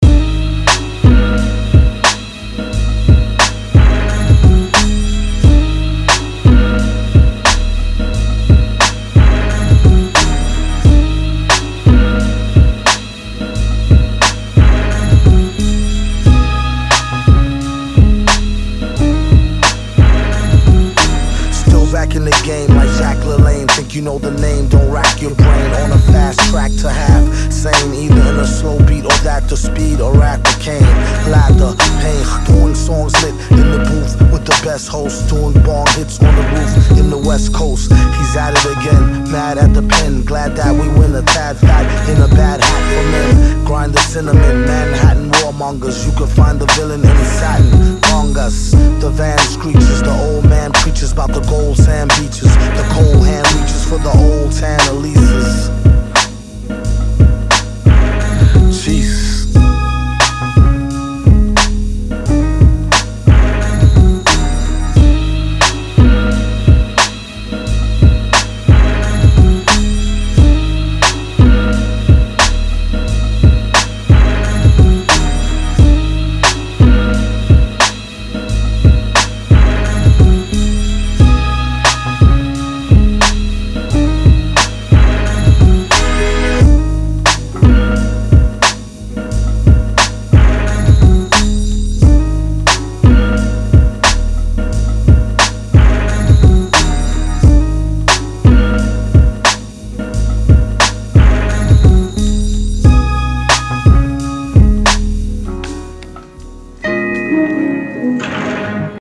the smooth dark prince of shadow beats.